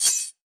PERC - POCKETS.wav